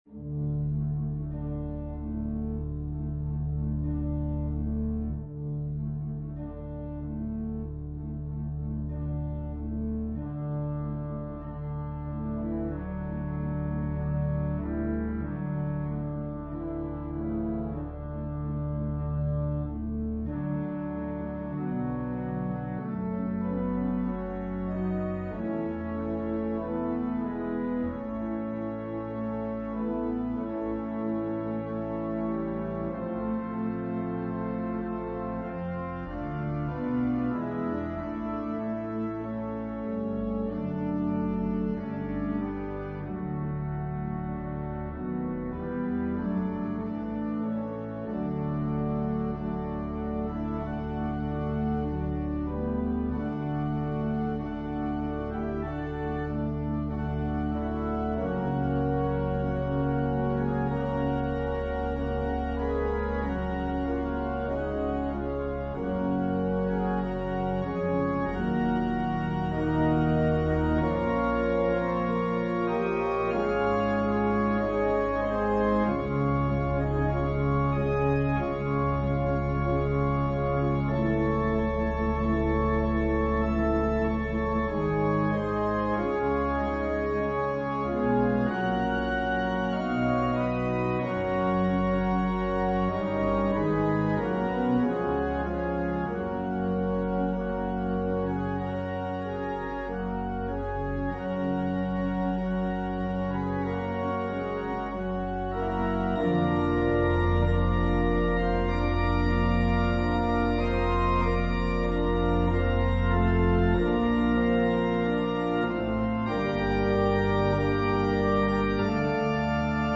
A solo organ arrangement
Voicing/Instrumentation: Organ/Organ Accompaniment